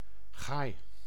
Ääntäminen
IPA : /ˈwʊdən/